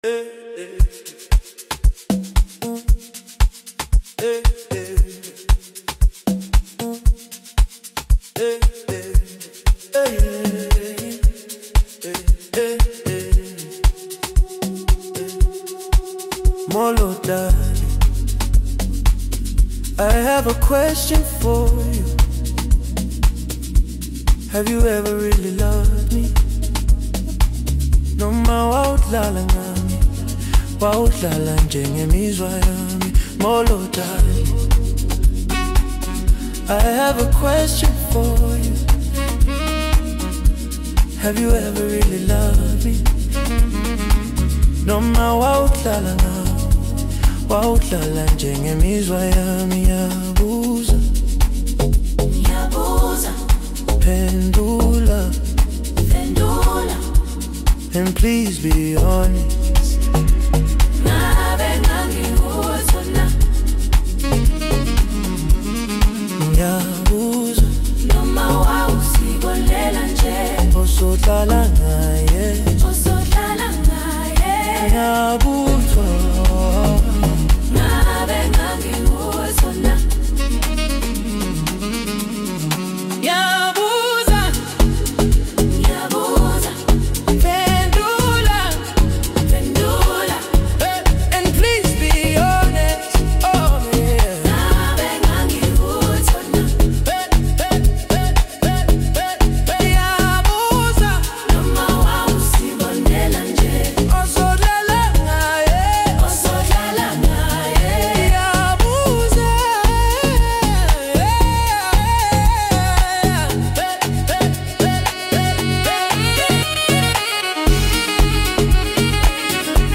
Home » Amapiano
South African singer-songwriter